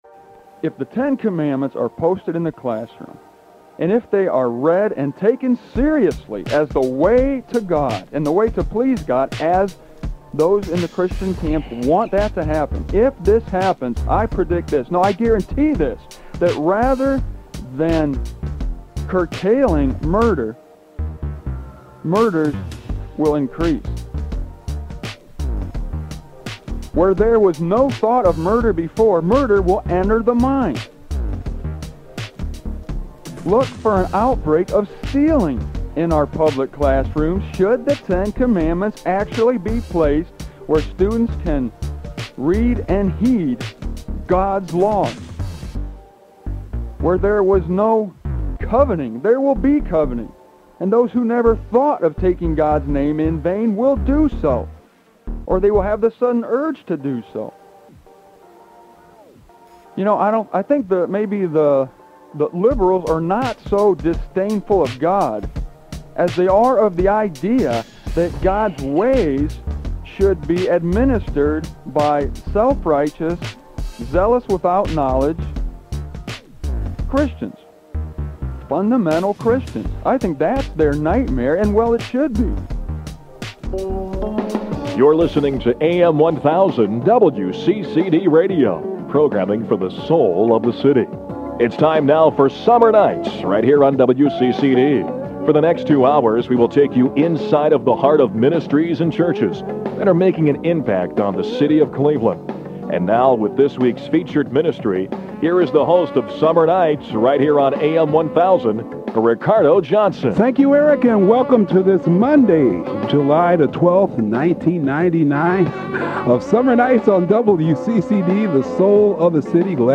Back in the summer of '99, when we recorded this broadcast at WCCD Christian Radio in Cleveland, Ohio, there was a big move to get the 10 Commandments posted in schools and in government buildings. We came out of the gate telling our audience what a terrible idea this would be and, really, how dangerous the 10 Commandments were in the hands of human beings.